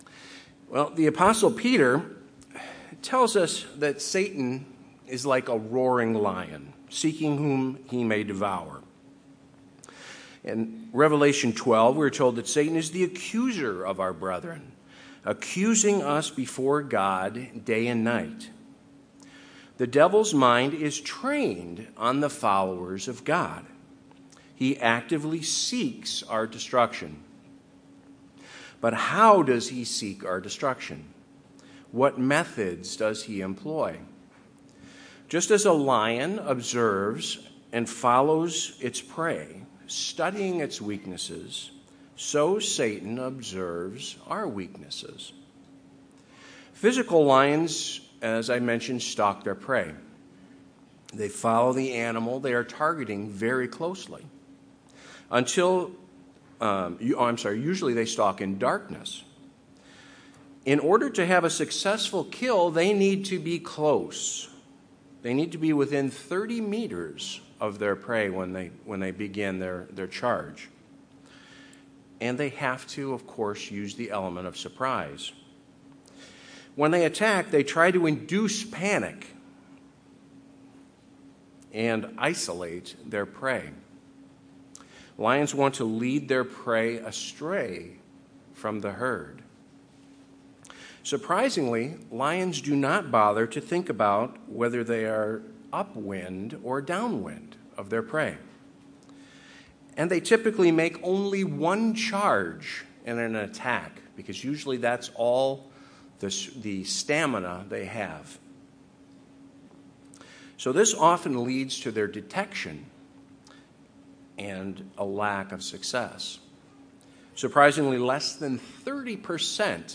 In this sermon, we examine the major strategies Satan employs against the people of God in order to lead them astray.
Given in Chicago, IL